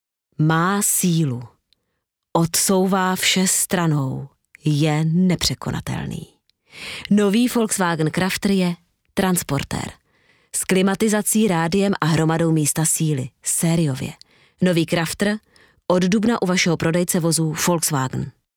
tschechisch, Tschechische Muttersprachlerin, Native Czech, Speaker, Voice-Over, Imagefilme, Comedy, Jigle, Hörspiele, Werbung, Deutsch mit Akzent, osteuropäischer Akzent, Moderatorin, TV, Rundfunk, Film, Stimmlage, dynamisch, facettenreich, frisch, mittelkräftig, seriös
Sprechprobe: Werbung (Muttersprache):
Native Czech, Speaker, Voice-Over, Bussines, Corporate, Video, e-learning, Presenter, Host, TV, Radio, Movie, Comedy, Events, English, eastern european accent, Trailer, German, Commercial, Narration, Documentary, Educational, Videos